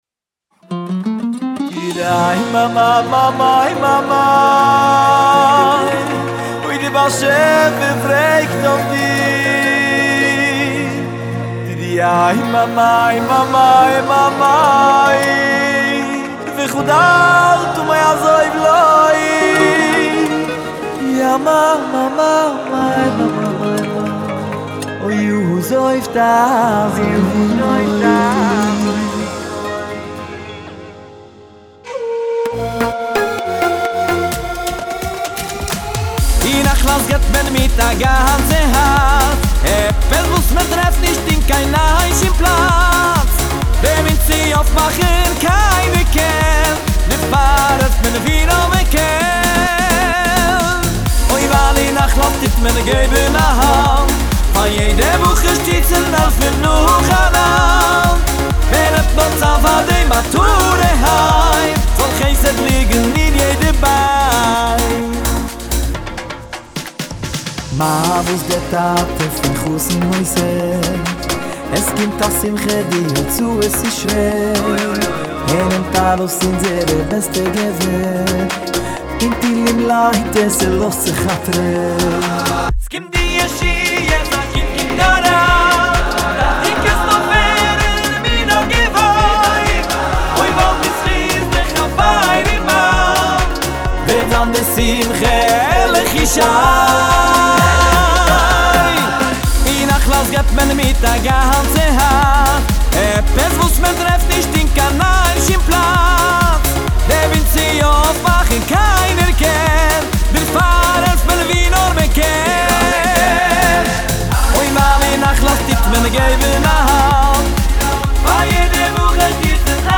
מקהלה